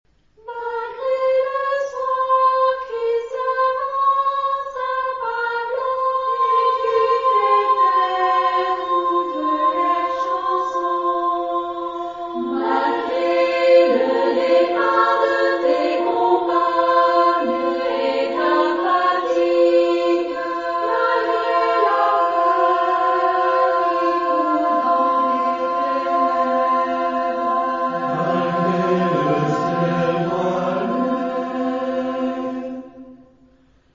Genre-Style-Form: Secular ; Poem
Mood of the piece: moving ; gentle
Type of Choir: SMAH  (4 mixed voices )
Tonality: B flat minor